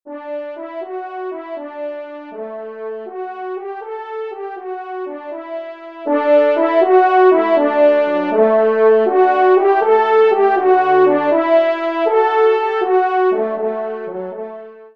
Genre : Divertissement pour Trompes ou Cors
Pupitre 3° Cor